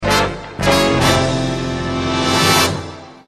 Intro-sound-effects.mp3